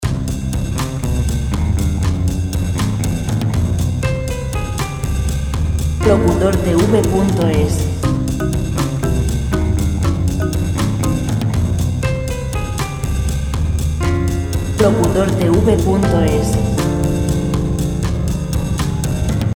Música  pop libre de derechos de autor.